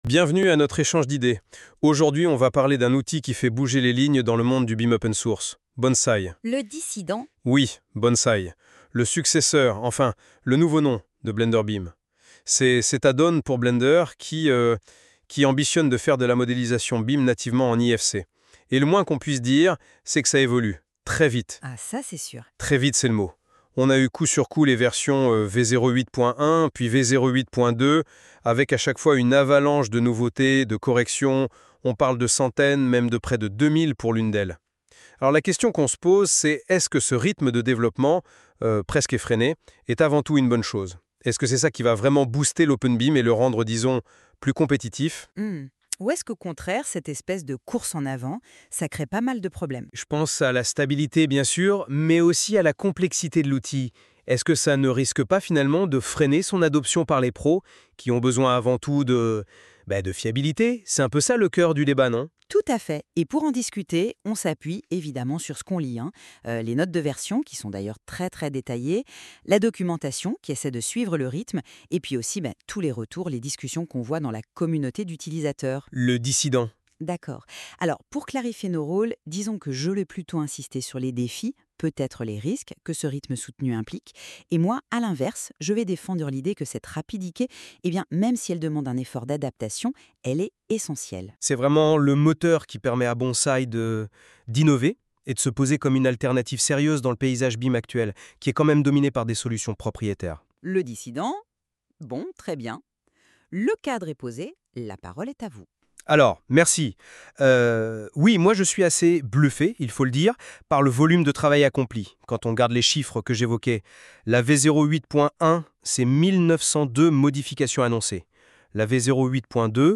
[!Success] Débat contradictoire /uploads/default/original/2X/0/0ed595fac39fac0dc237f7fe9c0c55ce65bff1ab.mp3